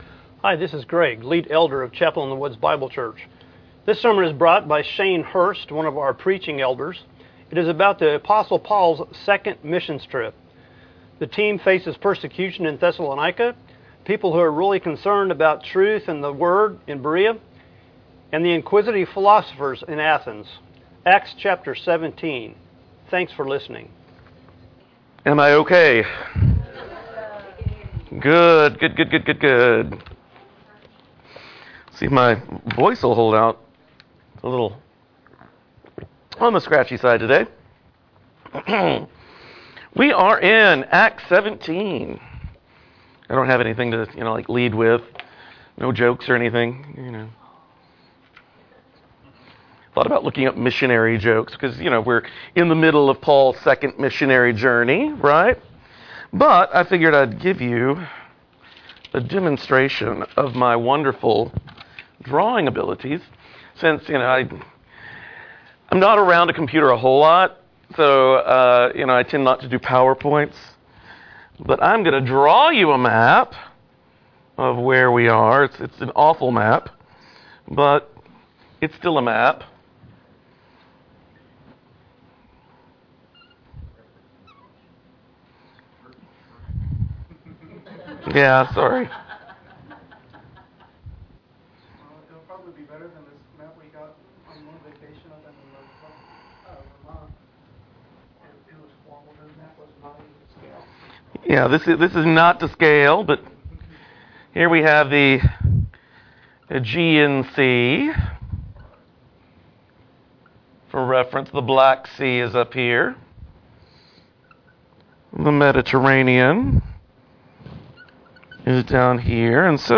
Jun 09, 2018 Acts 17 Thessalonica, Berea and Athens MP3 SUBSCRIBE on iTunes(Podcast) Notes Discussion Sermons in this Series Paul's 2nd missions trip. Persecution in Thessalonica, Perceptions of truth through the Word in Berea and Philosophers in Athens.